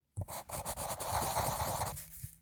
menu-exit-click.ogg